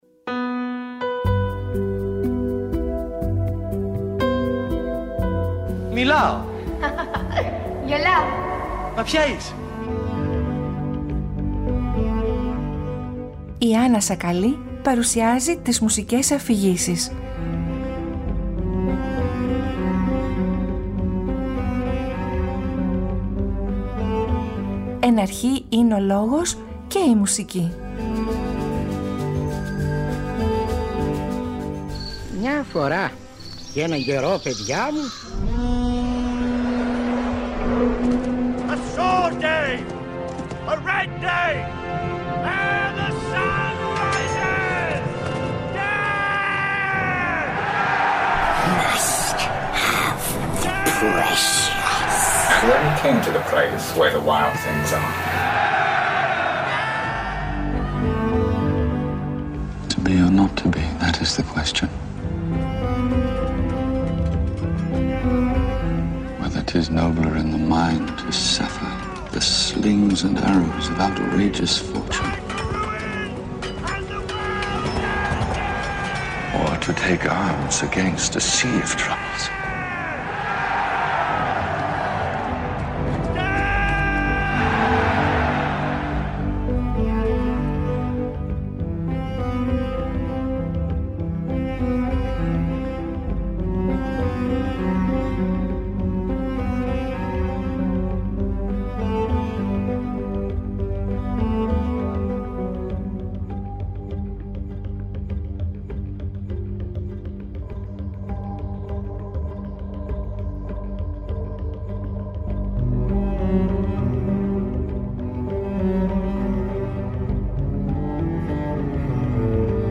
Πρόκειται για Επτά μουσικά κομμάτια για ορχήστρα και αφηγητή. Ένα κλασικό παραμύθι για τη σημασία της διαφορετικότητας και της αίσθησης του ανήκειν.